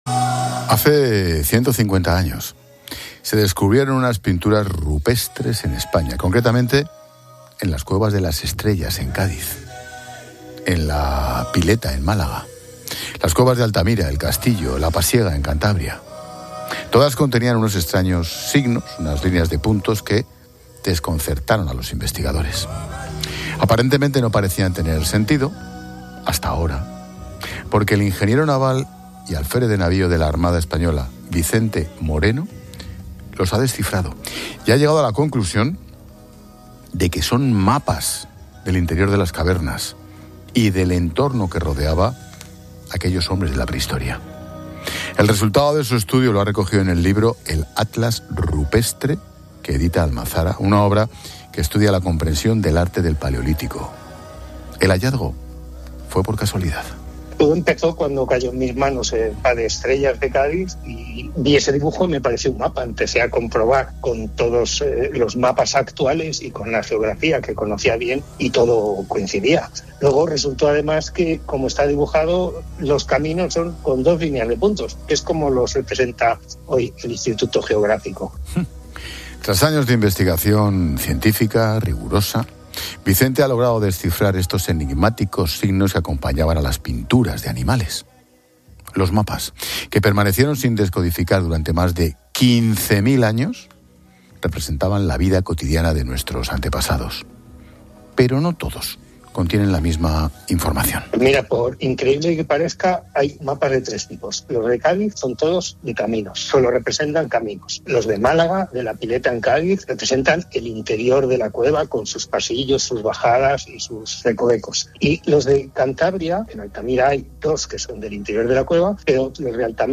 Expósito cuenta la historia de cómo han descifrado mapas prehistóricos en pinturas rupestres españolas